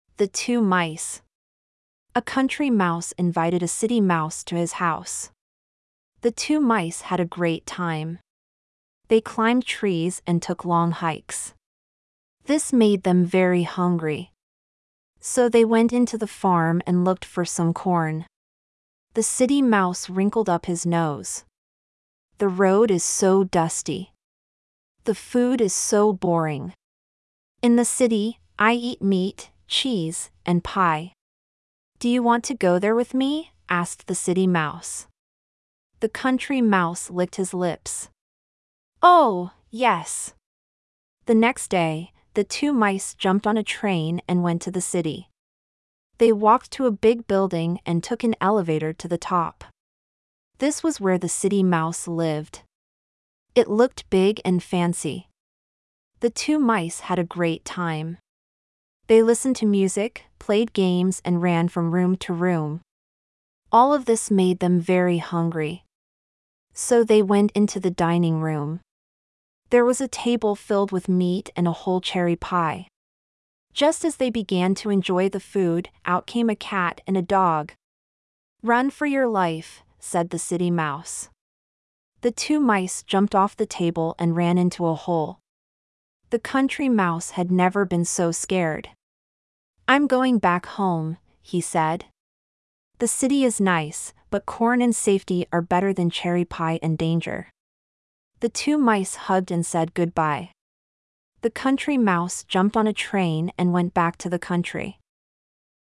113學年度大庄國小語文競賽英語朗讀文章及示範如附檔，請同學多加練習。